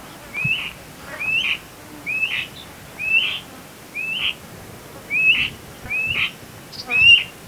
울음소리는 "퓨퓨" 하고 운다.
검은어깨매의 울음소리
평소에는 매우 조용하지만, 기록된 울음소리에는 높은 톤의 비명이나 부드러운 휘파람 소리가 포함된다.